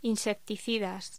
Locución: Insecticidas